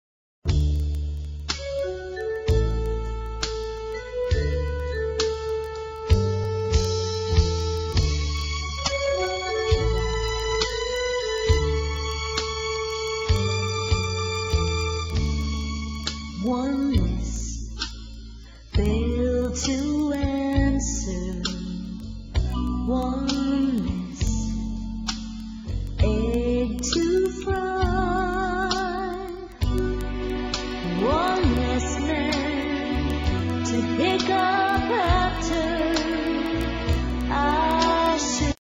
NOTE: Vocal Tracks 1 Thru 6